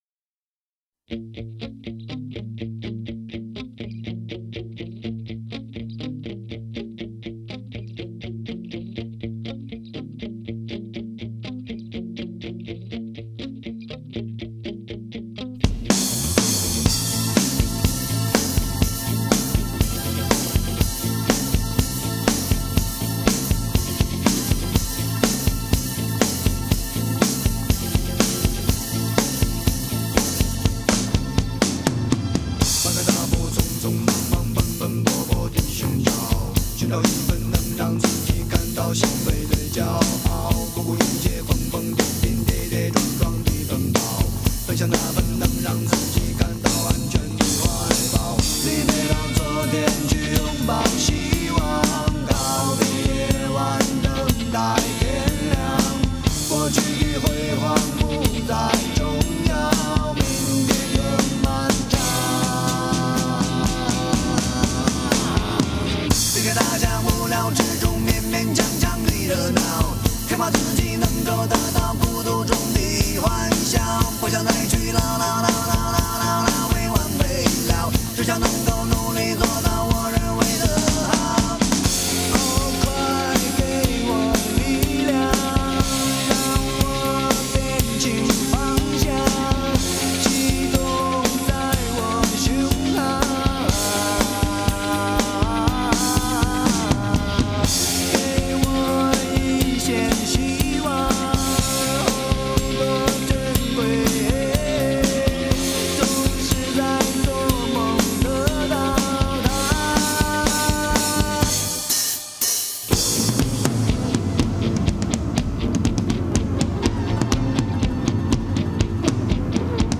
低沉的Bass带出一阵令人兴奋的鼓声，漫长的明天就这样开始了。